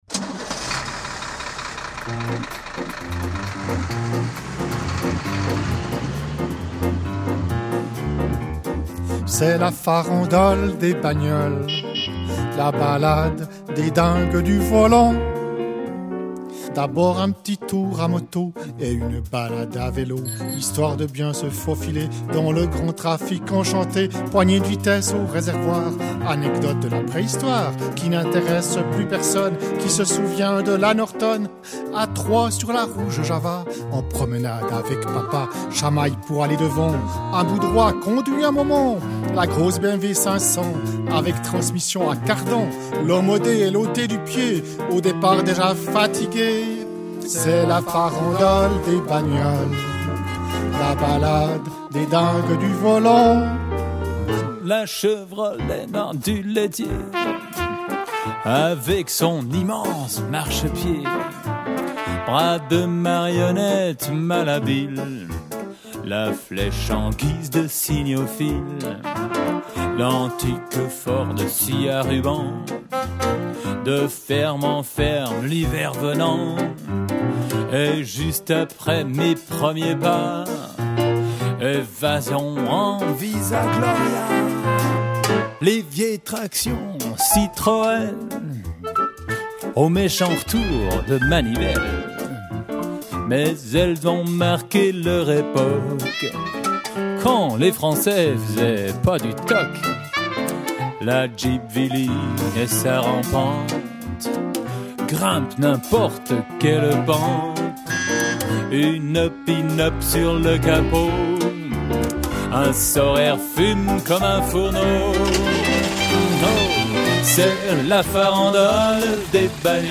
(blues, funk, disco, reggae, valse et autres airs du temps)